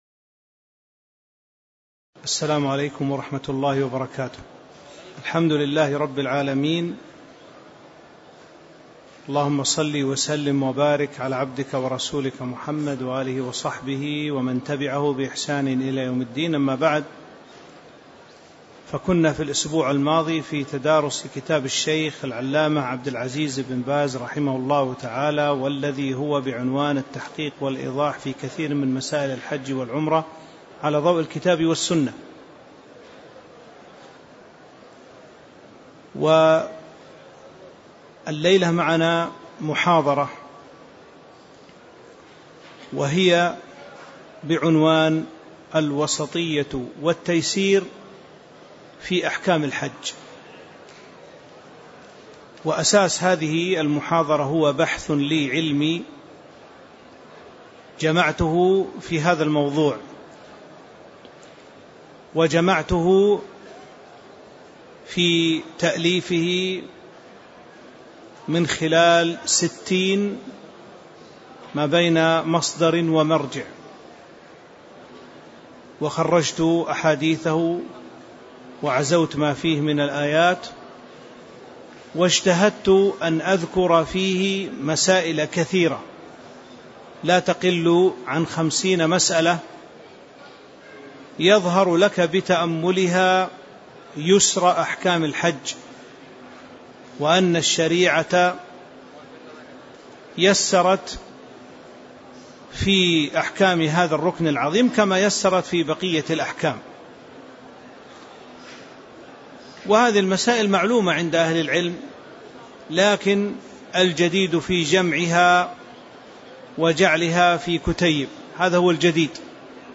تاريخ النشر ٢٦ ذو القعدة ١٤٤٤ هـ المكان: المسجد النبوي الشيخ